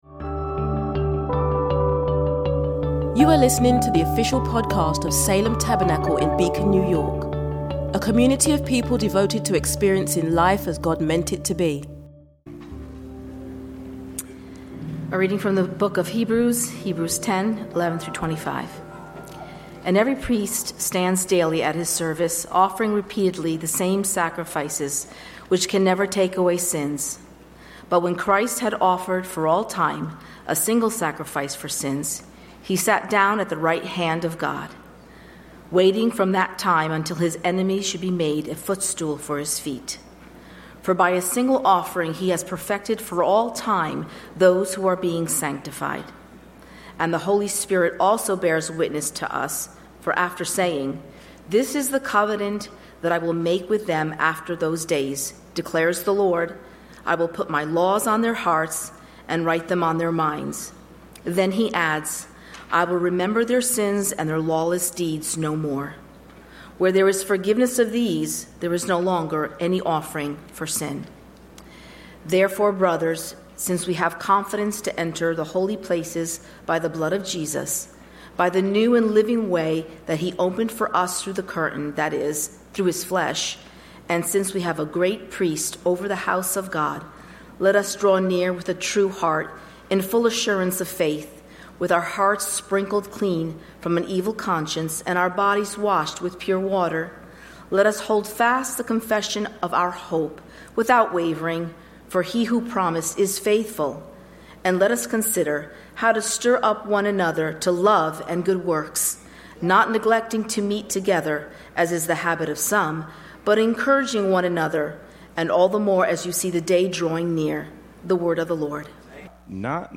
This Podcast is recorded from Salem Tabernacle in Beacon, NY.
From time to time there will be different speakers and preachers.